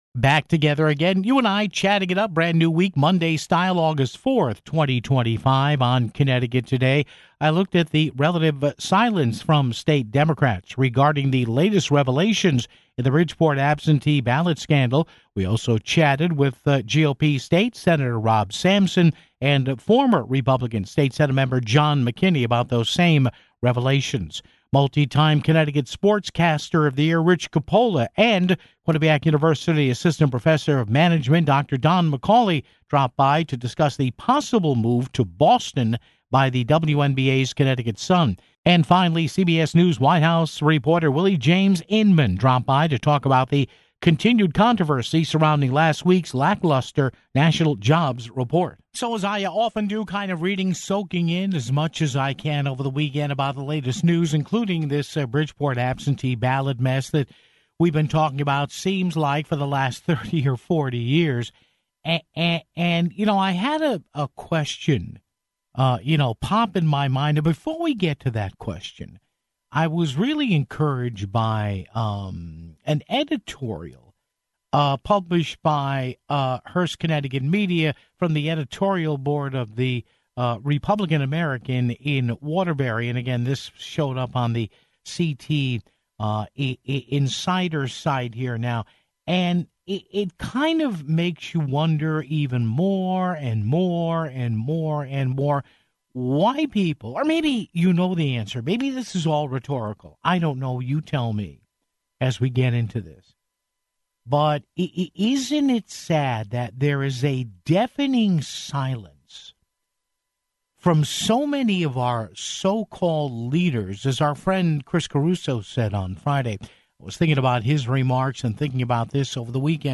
We also chatted with GOP State Sen. Rob Sampson (15:44) and former GOP State Sen....